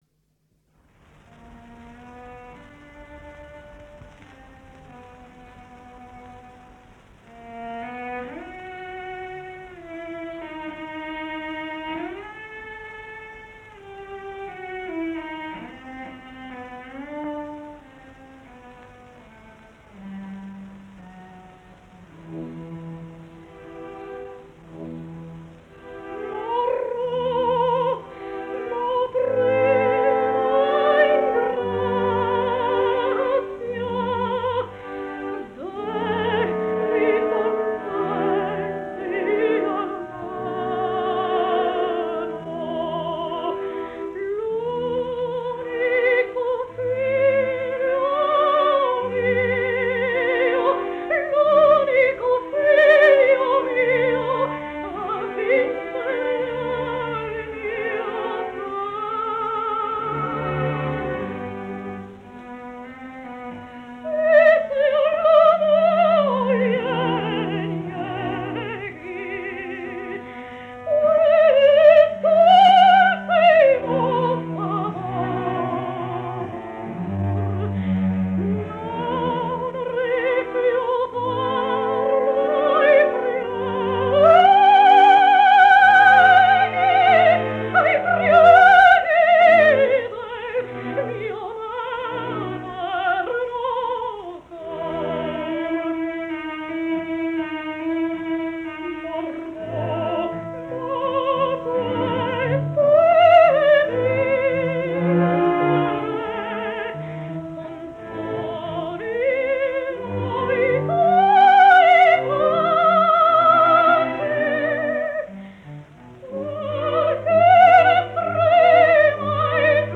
116 лет со дня рождения французской и итальянской певицы (сопрано), педагога Джины Чинья (Gina Cigna)